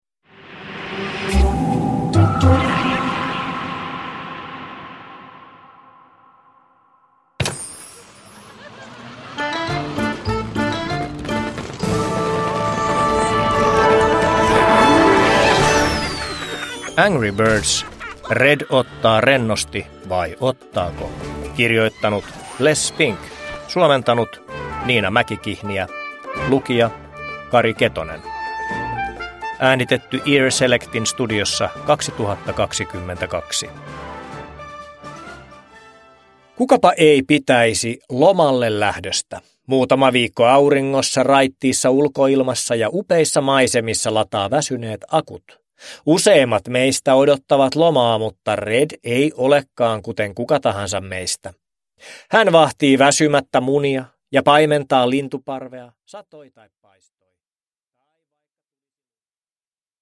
Uppläsare: Kari Ketonen